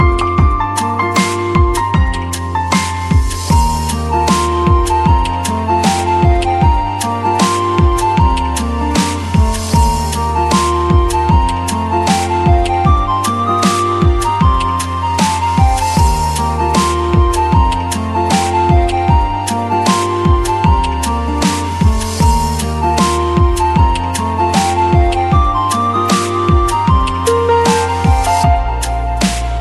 Kategorie Instrumentalny